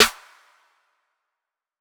Snare_Clap_01.wav